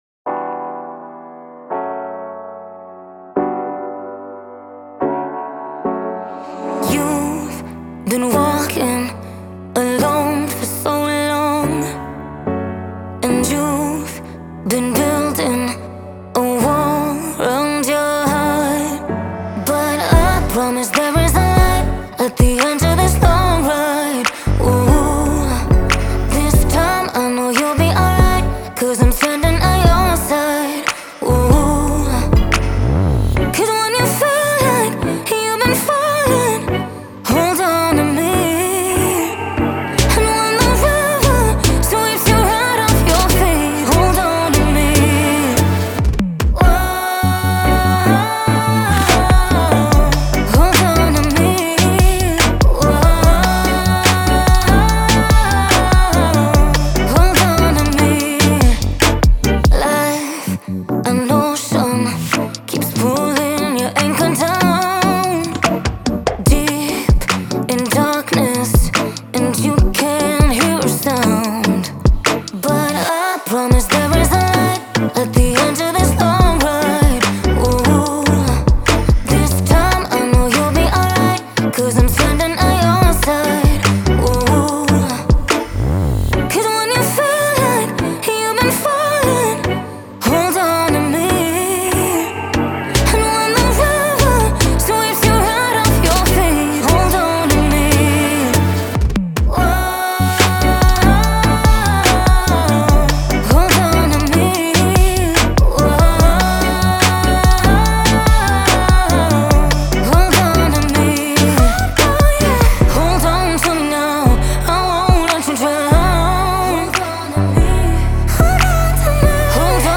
это эмоциональная поп-баллада